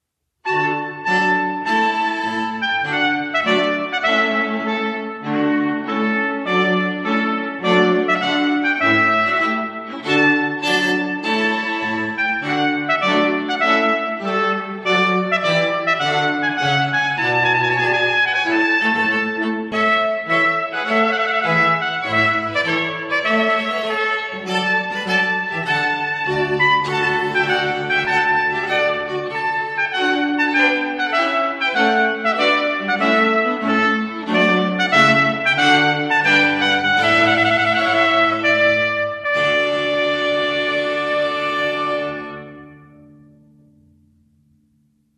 Trumpet
with Cathedral Ensemble String Quartet